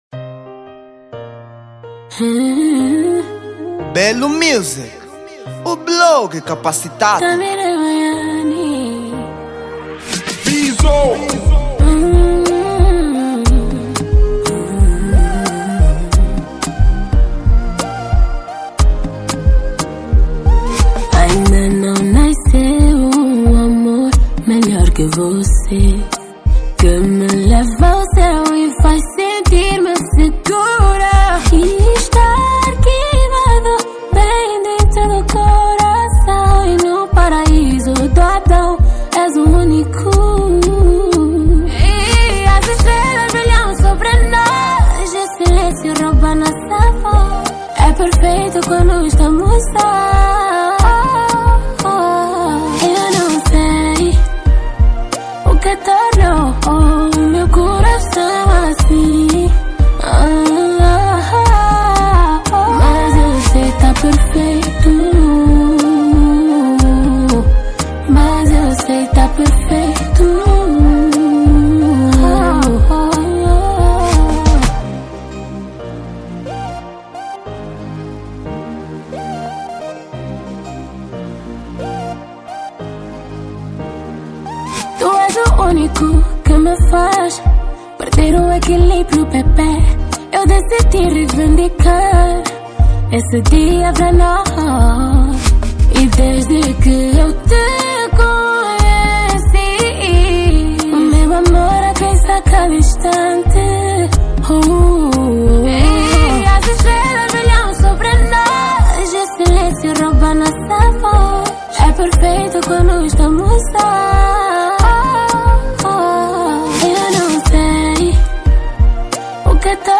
Género: Kizomba